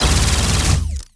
Added more sound effects.
GUNAuto_Plasmid Machinegun C Burst Unstable_01_SFRMS_SCIWPNS.wav